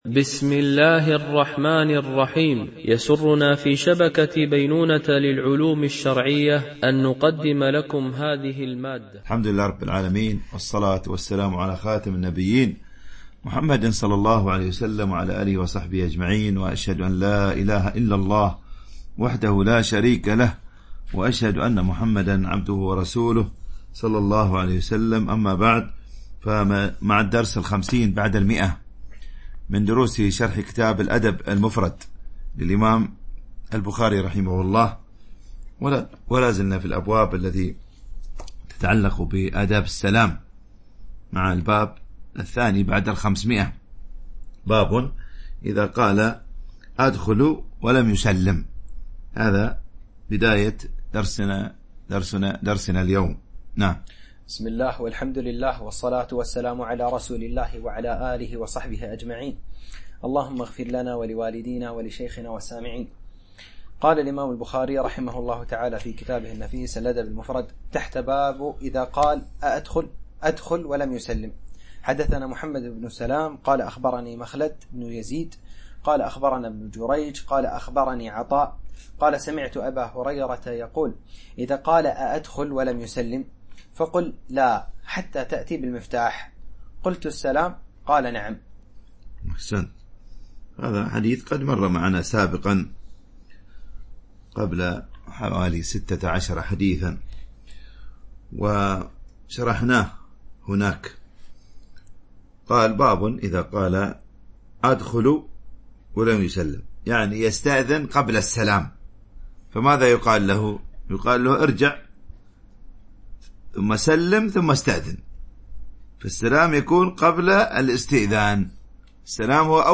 شرح الأدب المفرد للبخاري ـ الدرس 150 ( الحديث 1084 - 1093 )